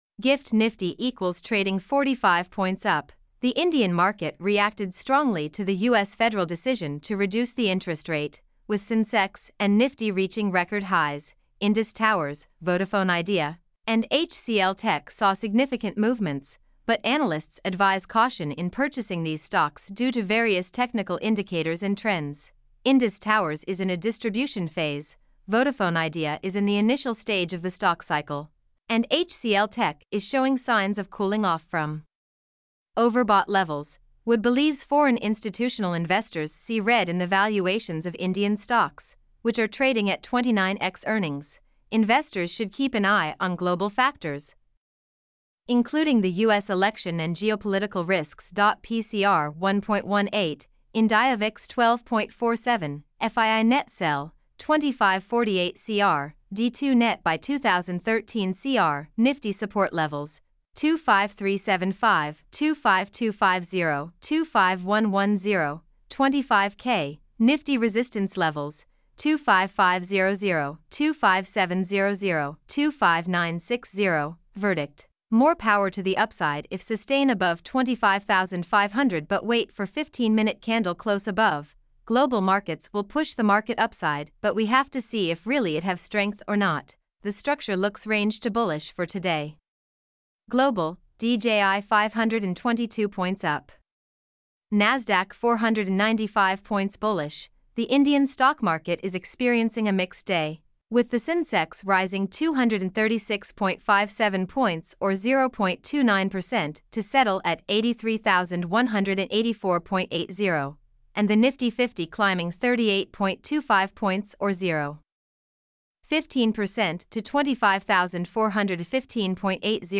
mp3-output-ttsfreedotcom-5.mp3